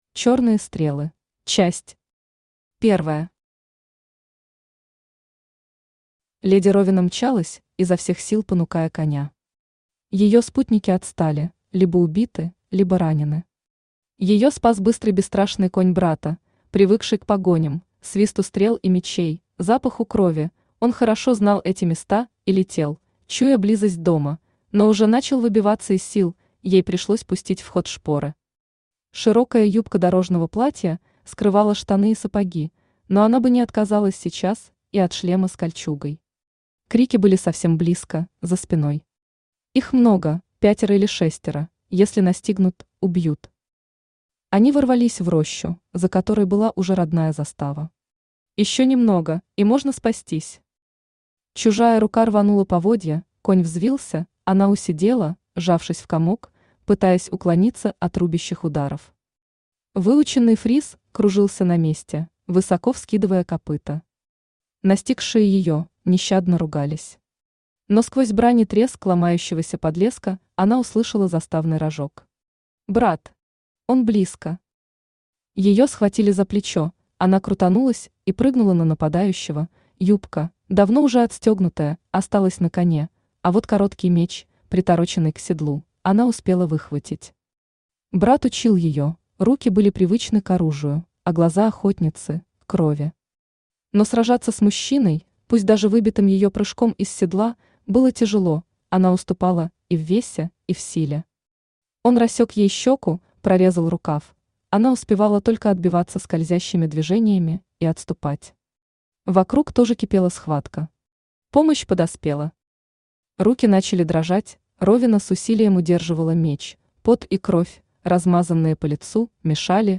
Аудиокнига Чёрные стрелы | Библиотека аудиокниг
Aудиокнига Чёрные стрелы Автор Елена Тальберг Читает аудиокнигу Авточтец ЛитРес.